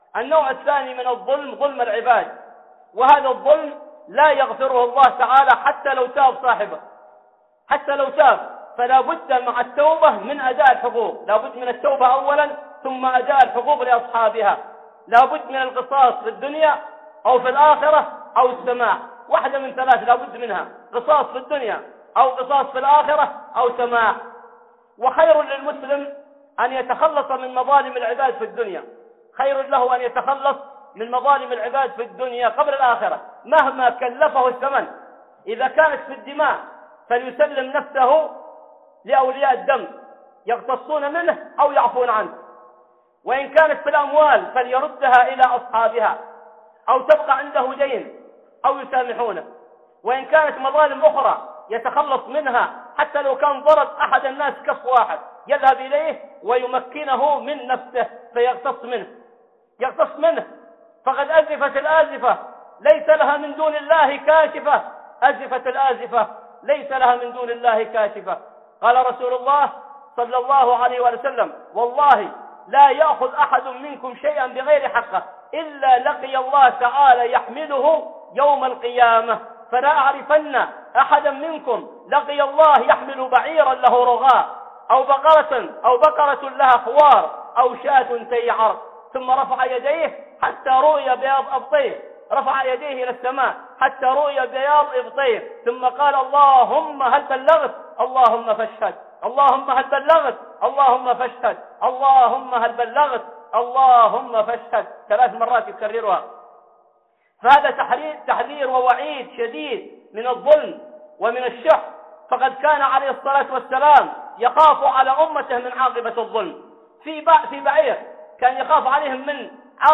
وجوب التحلل من مظالم العباد قبل ألا يكون درهم ولا دينار - خطب